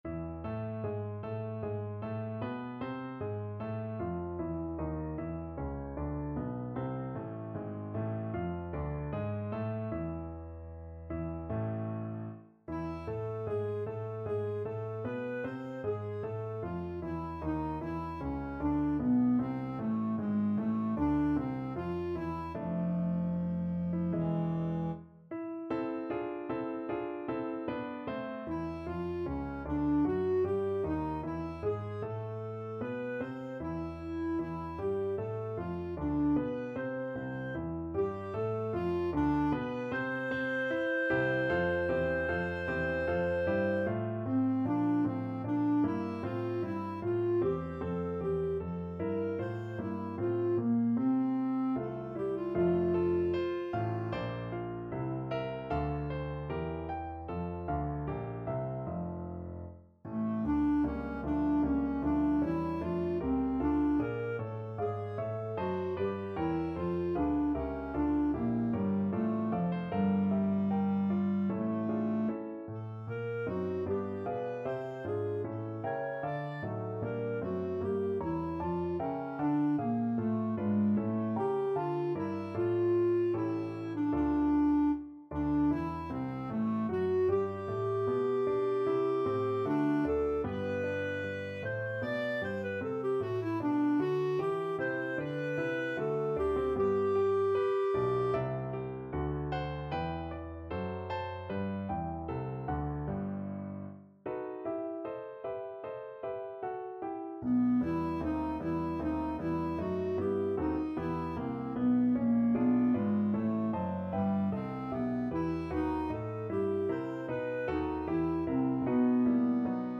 Clarinet
A minor (Sounding Pitch) B minor (Clarinet in Bb) (View more A minor Music for Clarinet )
Larghetto (=76)
Classical (View more Classical Clarinet Music)